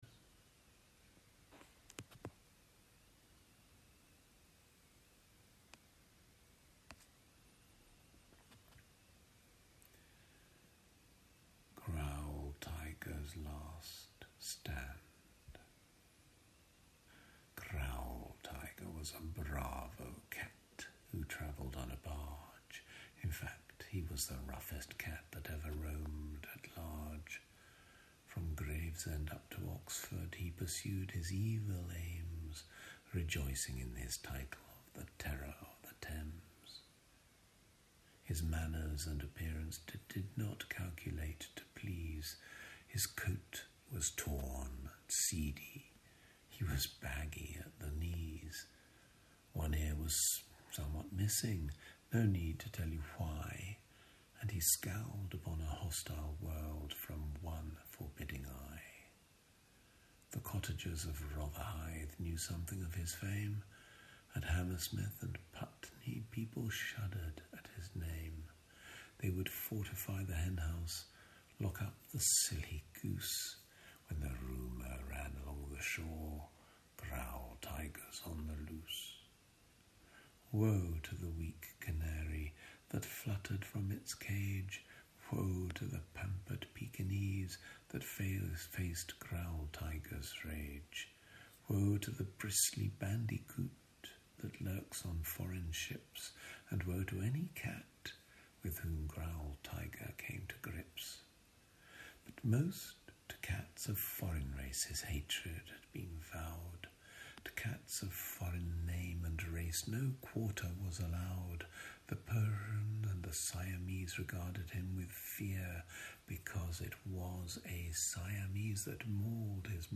TS Eliot A reading growltigers last stand